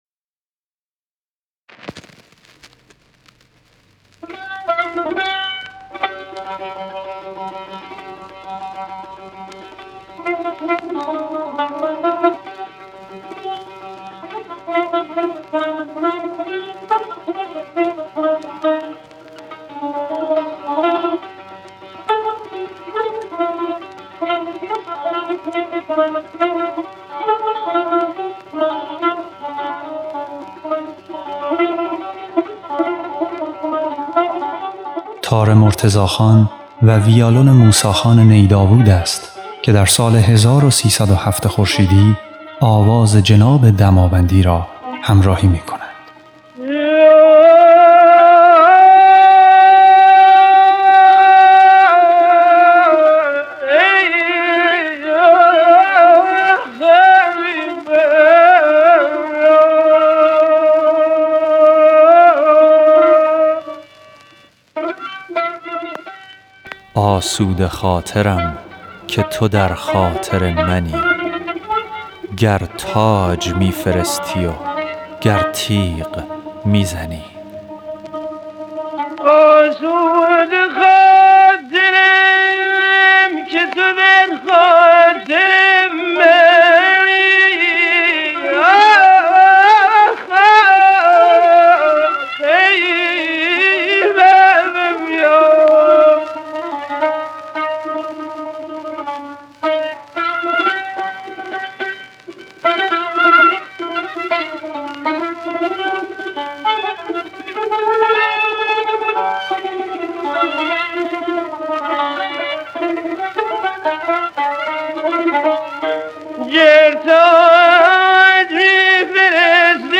صدایش دیگر طراوت جوانی را نداشت اما با عمق و پختگی خاصی همراه بود که تجربه زندگی و موسیقی در آن مشهود بود. همان‌گونه که زندگی از شور به آرامش ناشی از پختگی می‌رسد، آواز او نیز از تحریرهای پرهیجان به بیانی آرام تغییر جهت داده بود.
ابوعطا برخلاف برخی گوشه‌های شور که سرشار از طرب و انرژی‌اند، حالتی شاعرانه و لطیف دارد و شنونده را به تجربه‌ای تأمل‌برانگیز دعوت می‌کند.
تحریرها نرم و متعادل هستند و پایان هر جمله آوازی با فرود آرام صدا در سکوت تکمیل می‌شود. حضور تار و ویولن همراه، هماهنگی میان ردیف های قاجاری، صدادهی نوین و روح تغزل ایرانی را تقویت می‌کند.
تار و ویولن در این اثر به جای آنکه صرفاً ساز همراه باشند، با هر حرکت و پاسخ ملودیک گفتگویی زنده برقرار می‌کنند.
خواننده
مرتضی خان نی داوود نوازنده تار در جوانی
نوازنده تار
موسی خان نی داوود نوازنده ویالون
نوازنده ویولن